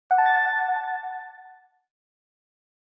snd_die.ogg